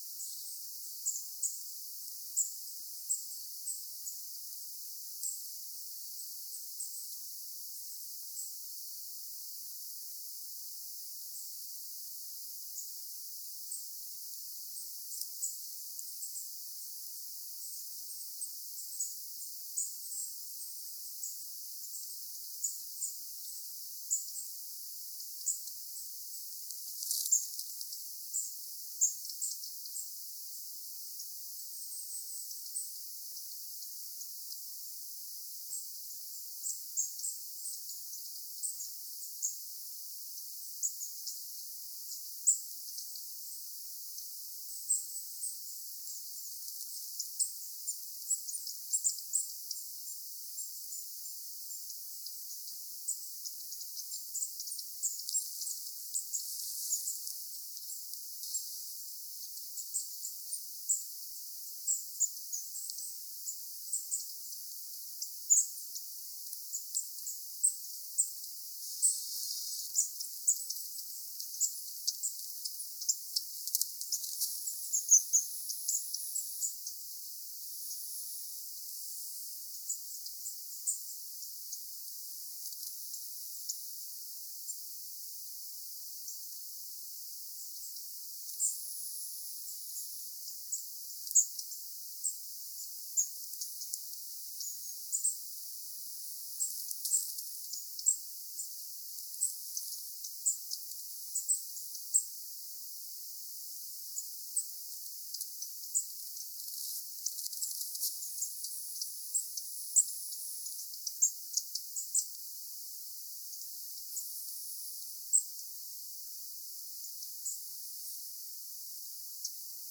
Sellaisia tarmokkaampia, sähköisempiä,
Äänite: sellaisia tarmokkaampia "voimalinjaääniä"
kahdeksan pyrstötiaisen parvi ääntelee
kahdeksan_pyrstotiaisen_aantelyja.mp3